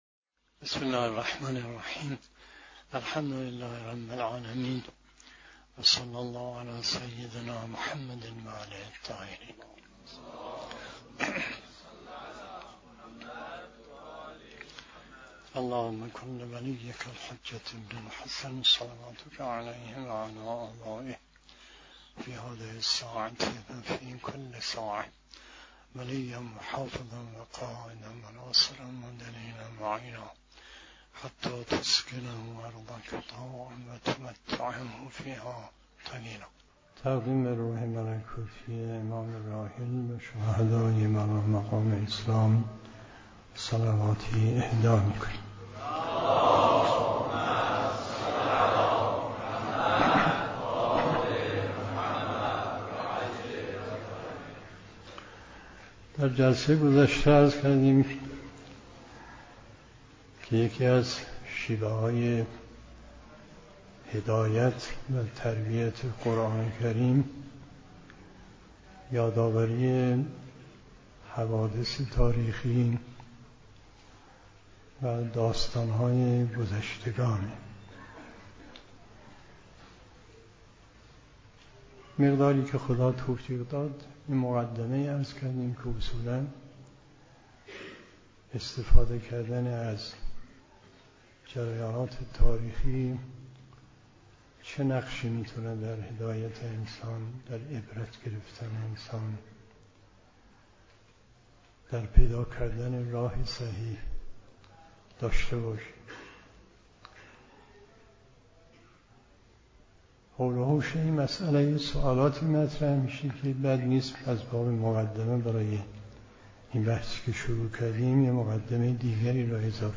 محل سخنرانی: دفتر مقام معظم رهبری در قم